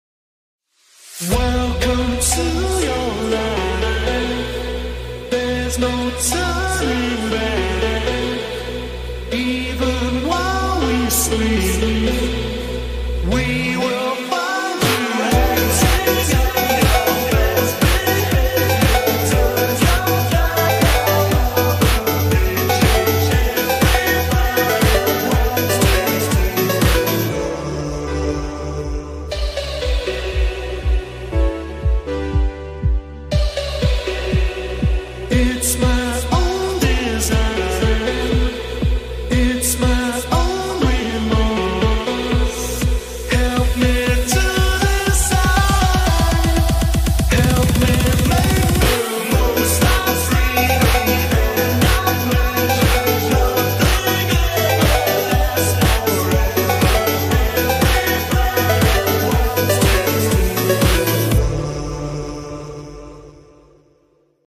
Титан камераман фонк скидиби ремикс